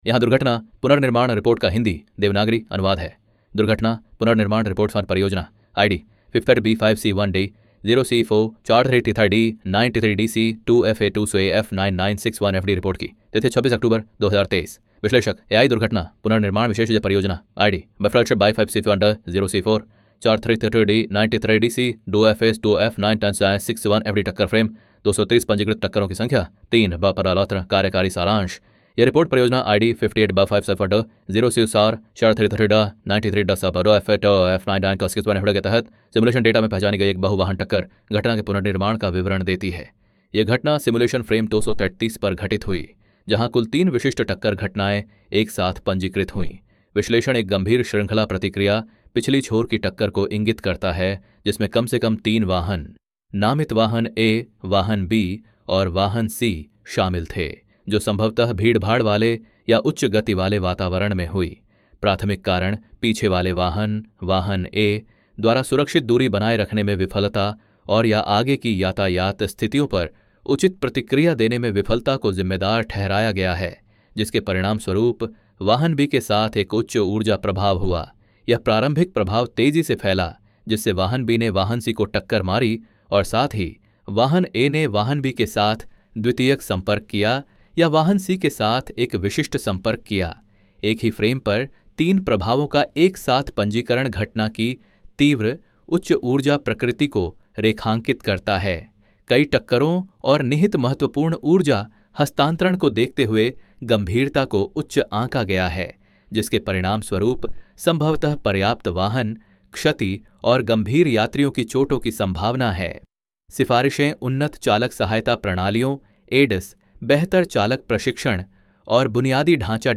To make insights easier to consume, CollisionCloud also produces spoken summaries of each accident.
Hindi Audio Report Sample